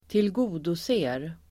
Uttal: [²tilg'o:dose:r]